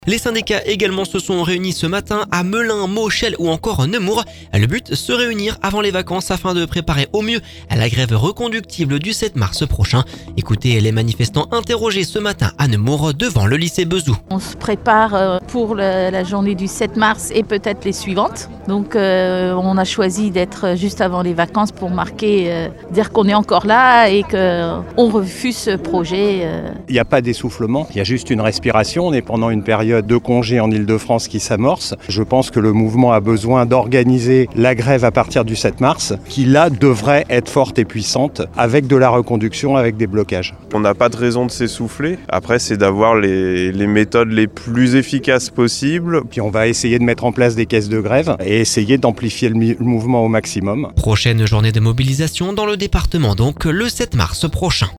Le but ? Se réunir avant les vacances avant de préparer au mieux la grève reconductible du 7 mars. Écoutez les manifestants intérrogés ce matin à Nemours devant le lycée Bezout…